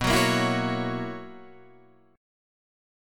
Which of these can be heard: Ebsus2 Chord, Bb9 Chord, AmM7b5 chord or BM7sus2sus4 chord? BM7sus2sus4 chord